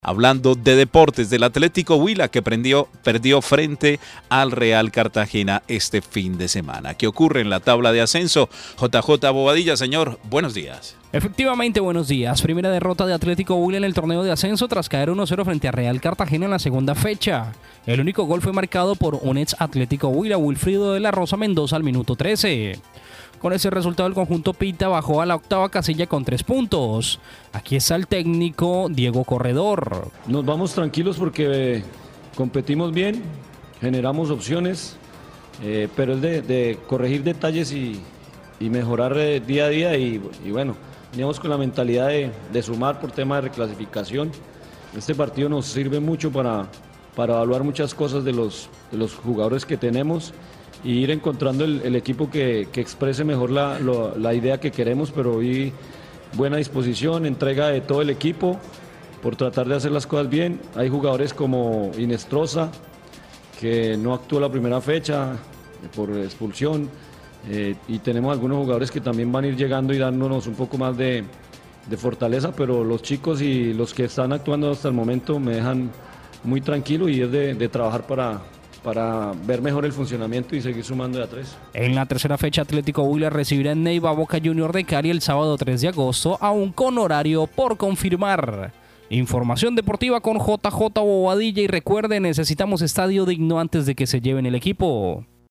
voces y protagonistas